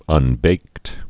(un-bākt)